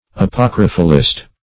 Search Result for " apocryphalist" : The Collaborative International Dictionary of English v.0.48: Apocryphalist \A*poc"ry*phal*ist\, n. One who believes in, or defends, the Apocrypha.